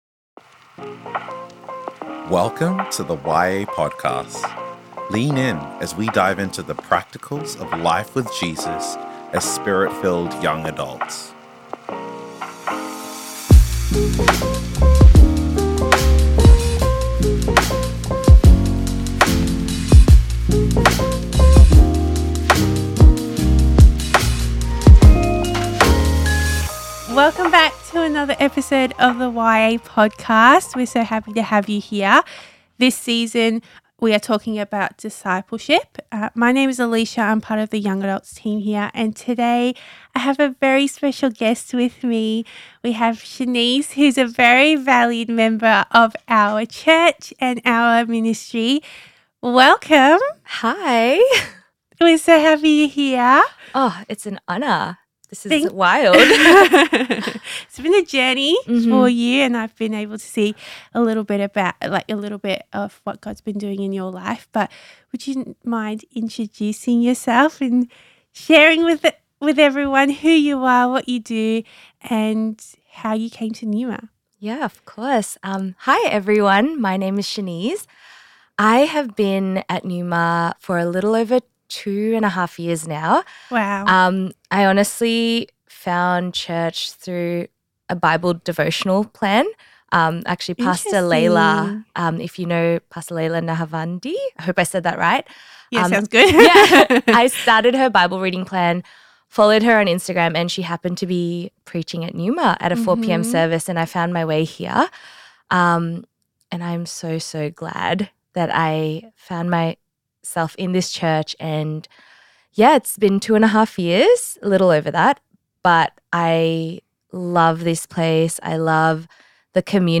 Discipleship Conversations: Vulnerability and Humility